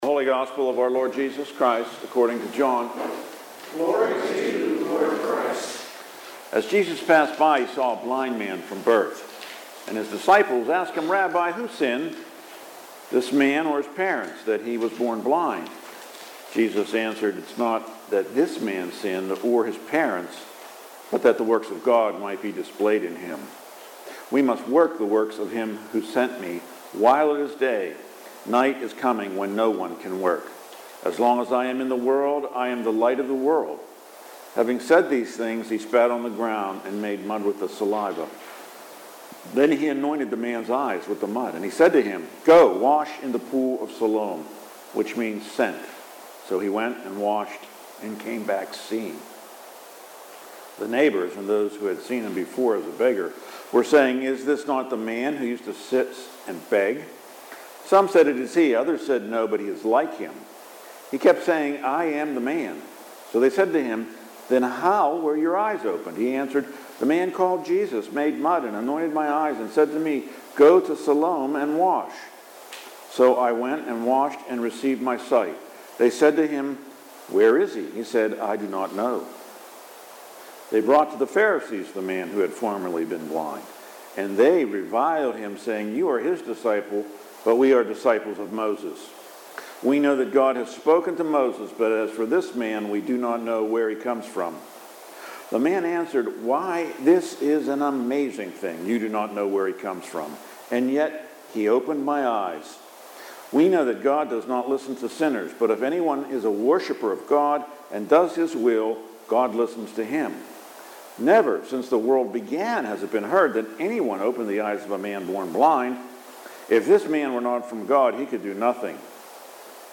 Readings and Sermon March 15 – Saint Alban's Anglican Church